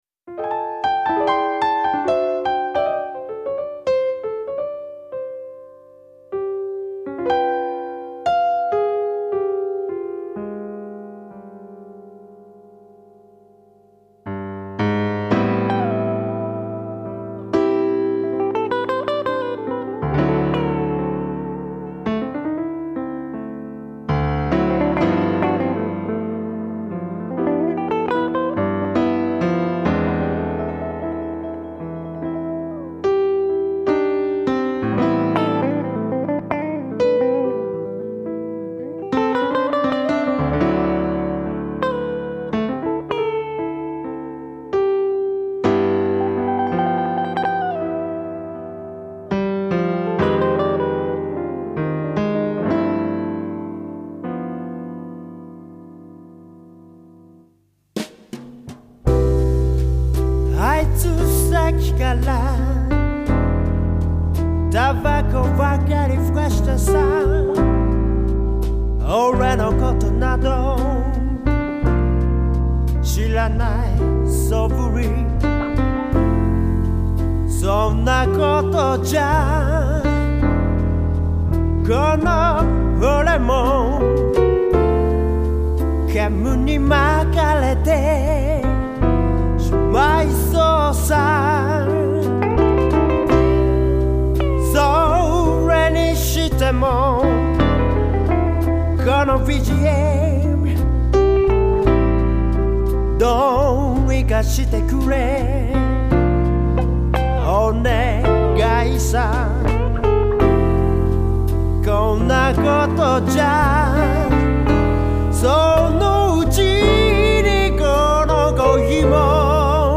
【vocal&guitar】
【bass】
【vocal&percussion】
【keyboard】